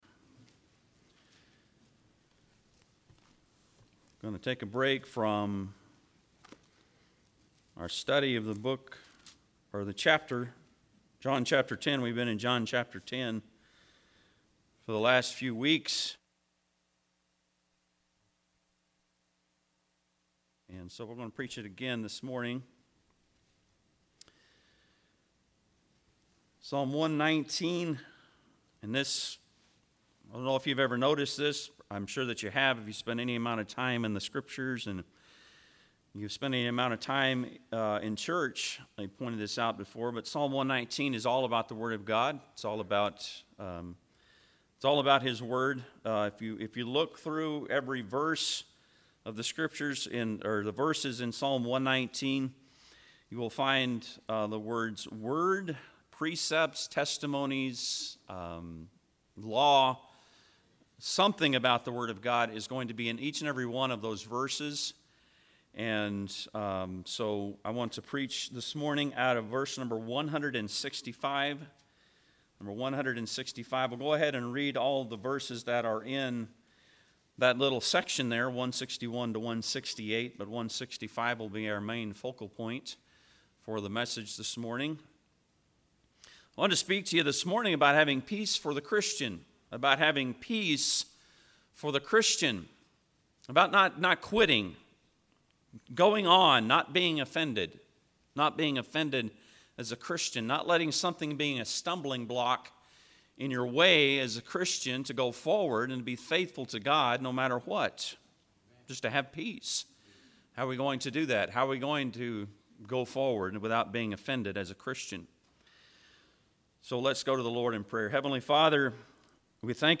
Psalm 119:165 Service Type: Sunday am Bible Text